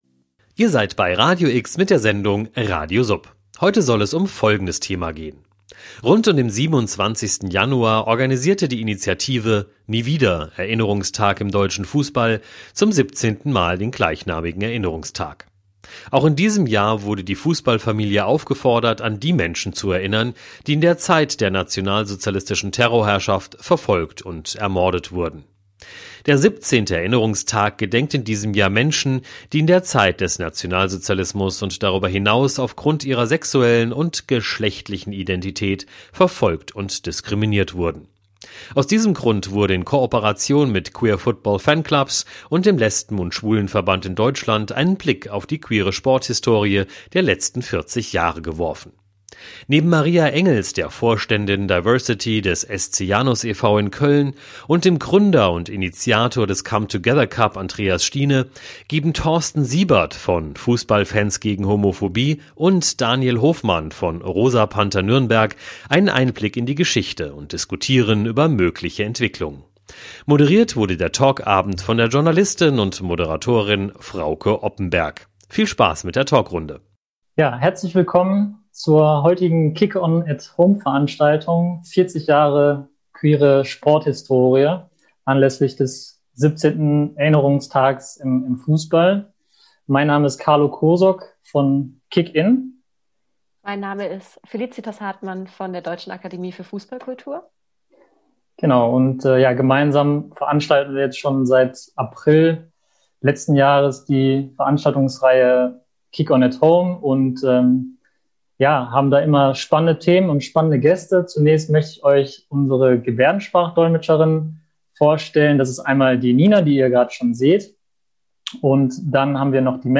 Moderiert wird der Talkabend